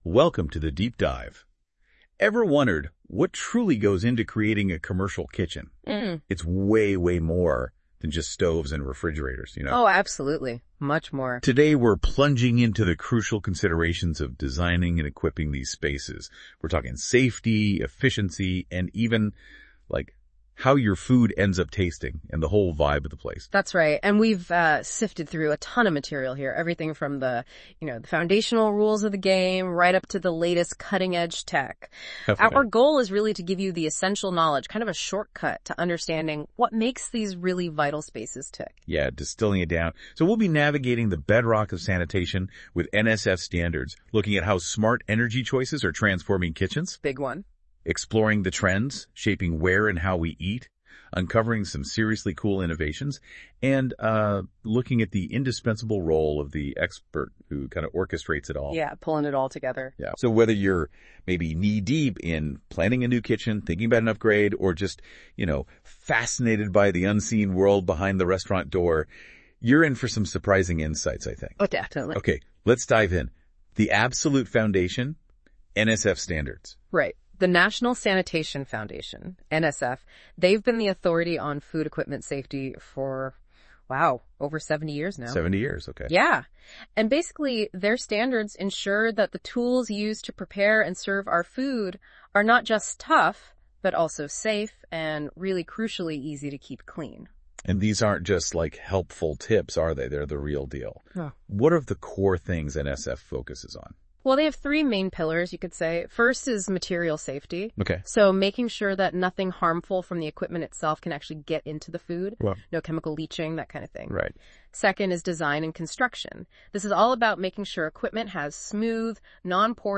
Whether you’re planning a new kitchen or rethinking an existing one, this conversation gives you the tools to think smarter and design better.